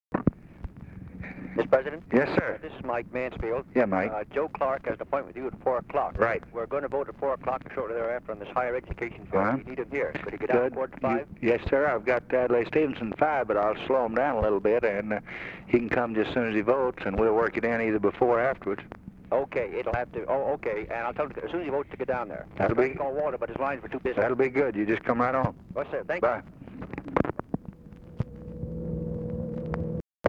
Conversation with MIKE MANSFIELD, December 10, 1963
Secret White House Tapes